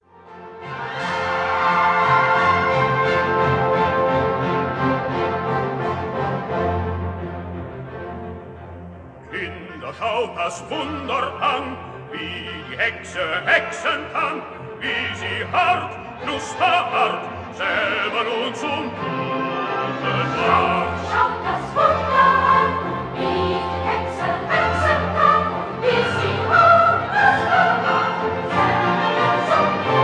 soprano
contralto
baritone
The Choirs of Loughton High School